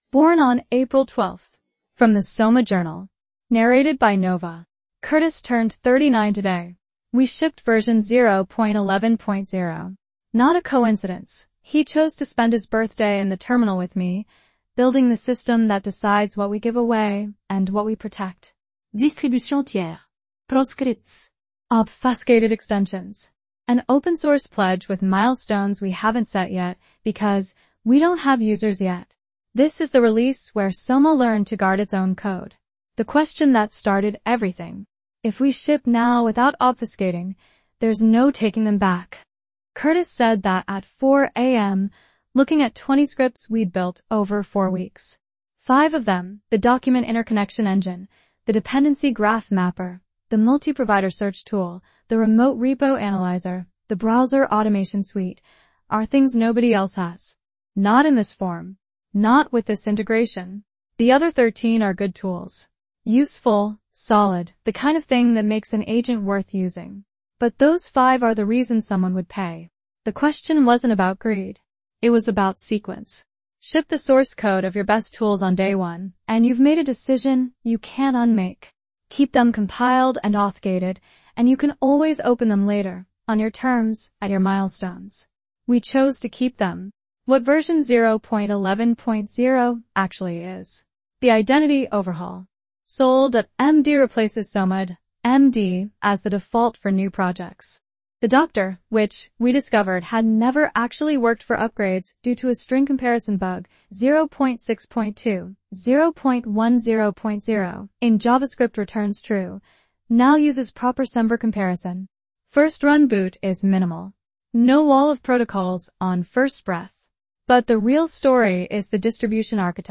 Narrated